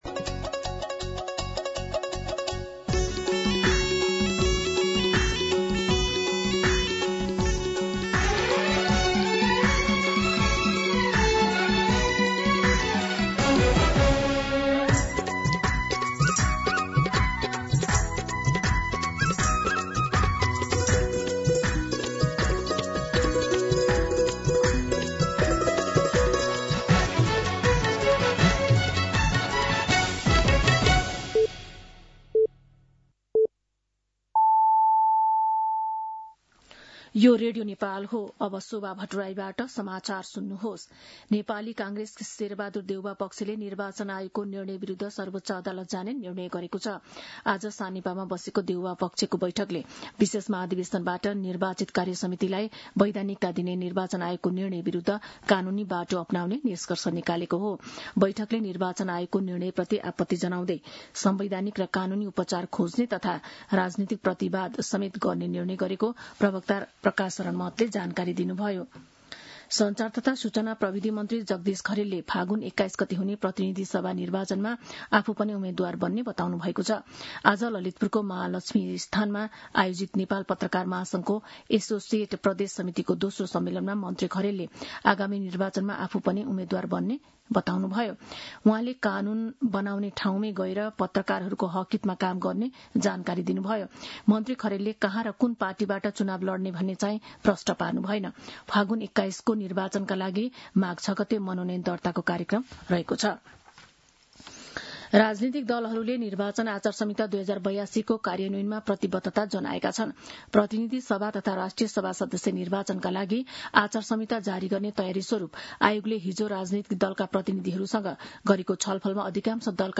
दिउँसो १ बजेको नेपाली समाचार : ३ माघ , २०८२
1pm-Nepali-News.mp3